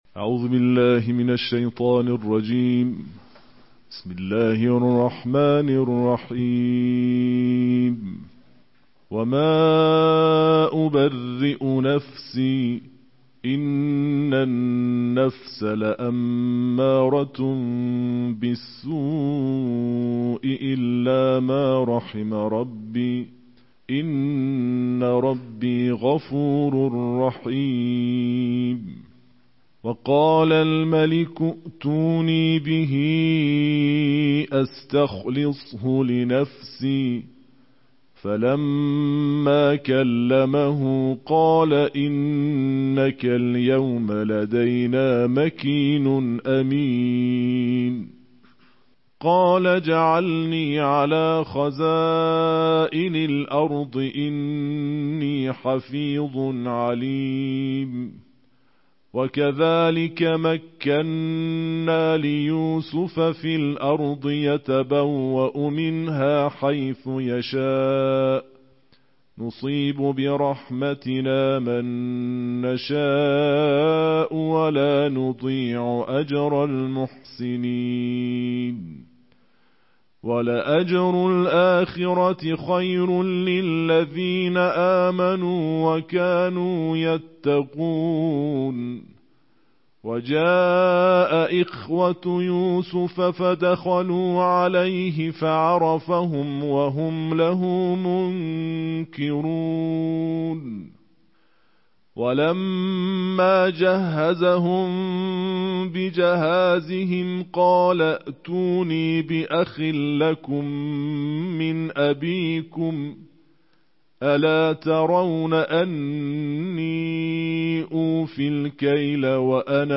Daily Quran Recitation: Tarteel of Juz 13